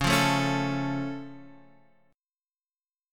Db+ chord